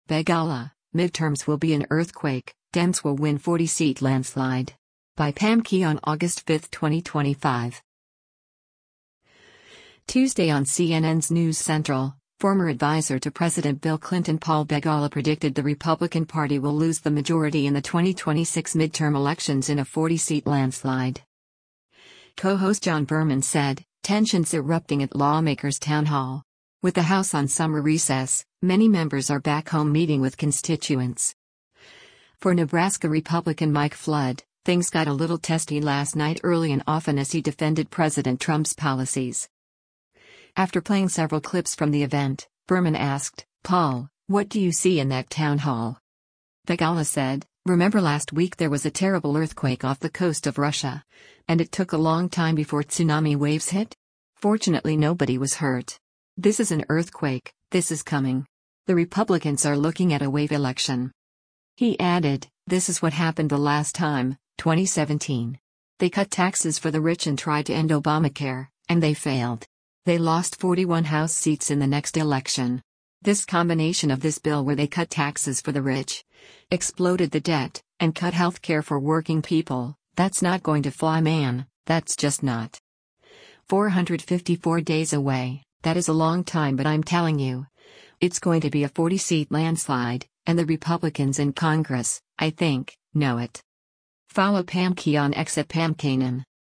Tuesday on CNN’s “News Central,” former advisor to President Bill Clinton Paul Begala predicted the Republican Party will lose the majority in the 2026 midterm elections in a “40-seat landslide.”
After playing several clips from the event, Berman asked, “Paul, what do you see in that town hall?”